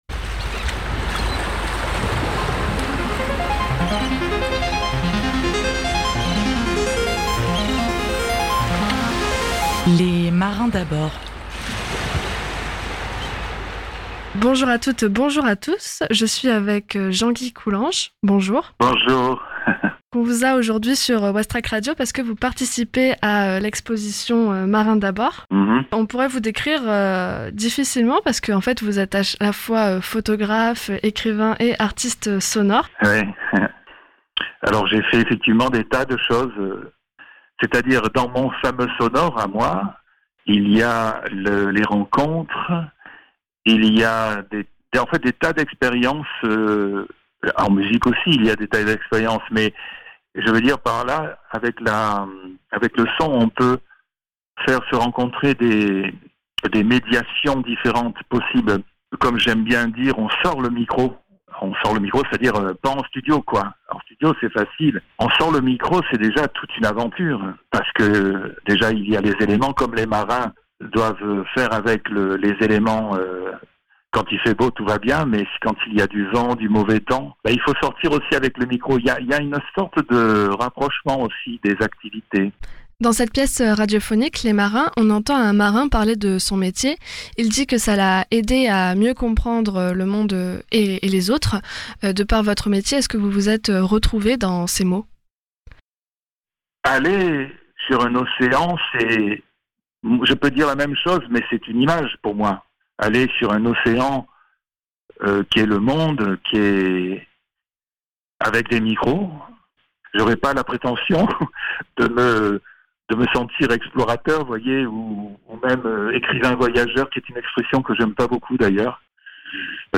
Le son est composé de la voix des marins et d’enregistrements électroacoustiques de l’espace portuaire havrais.